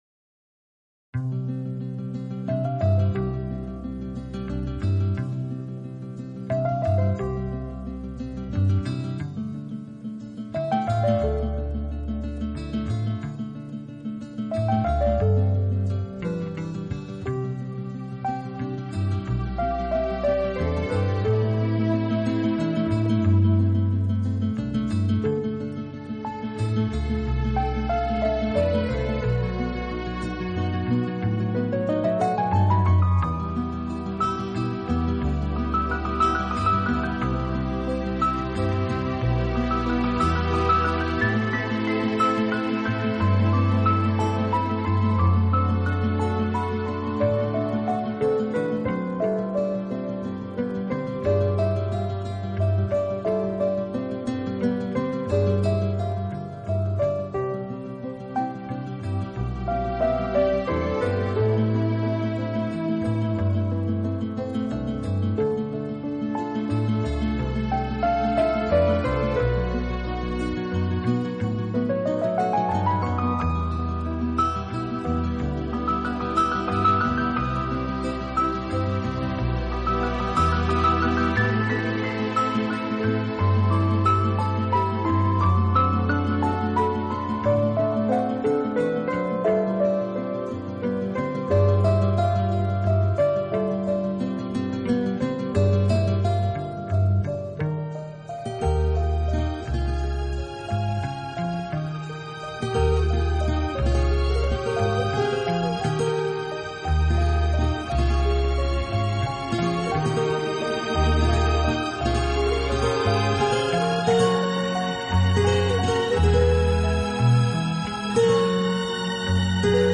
音乐风格：Easy-Listening | Instrumental